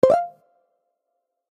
notification.oga